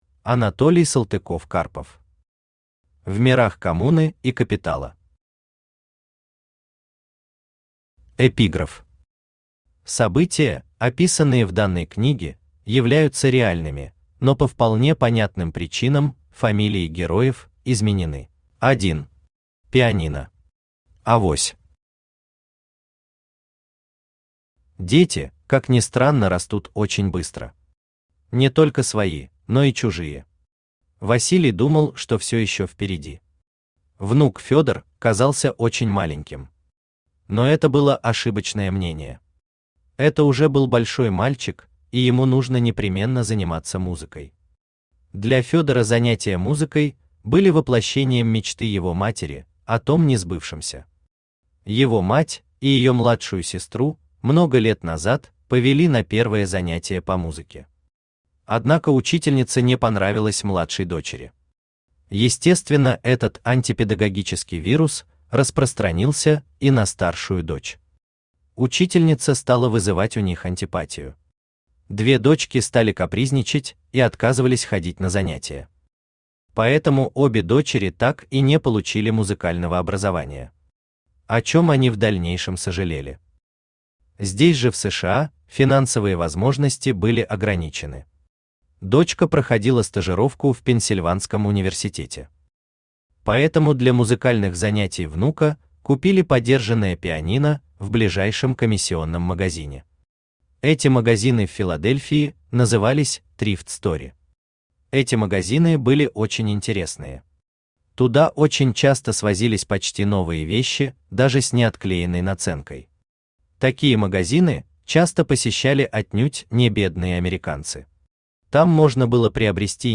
Aудиокнига В мирах коммуны и капитала Автор Анатолий Сергеевич Салтыков-Карпов Читает аудиокнигу Авточтец ЛитРес.